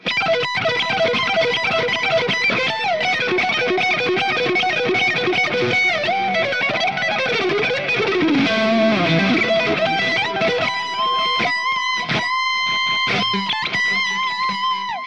This one does it with great balance and alot of bite!
Lead
RAW AUDIO CLIPS ONLY, NO POST-PROCESSING EFFECTS
Hi-Gain